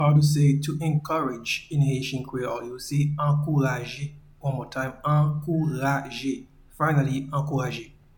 Pronunciation and Transcript:
to-Encourage-in-Haitian-Creole-Ankouraje.mp3